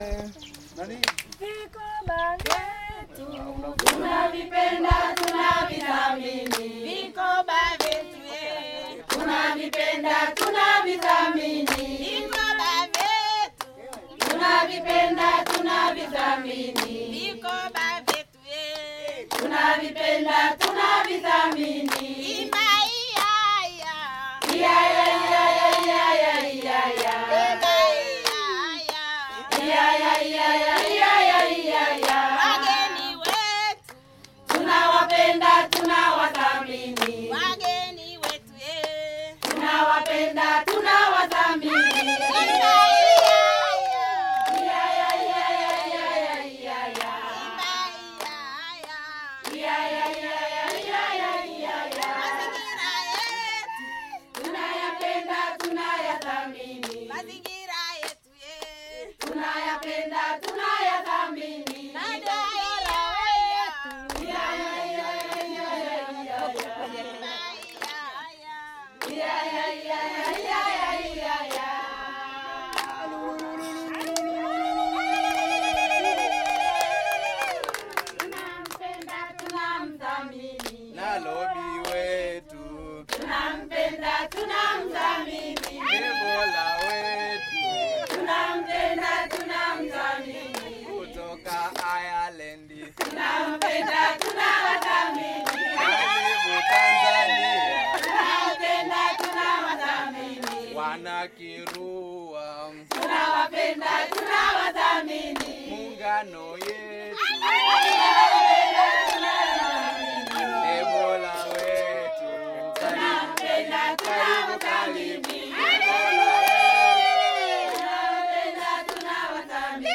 Micro Financing Group - Welcome Song